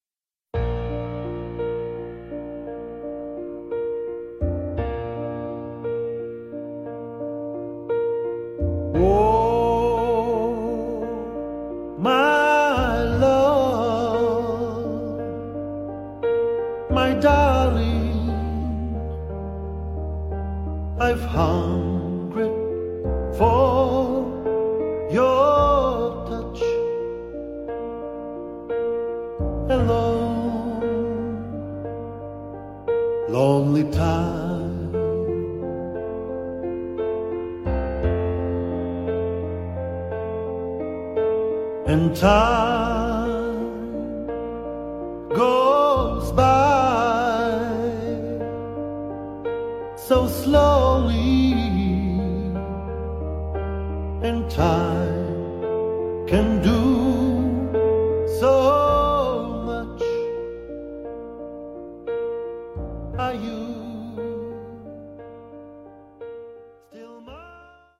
• Pianist / Orgelspieler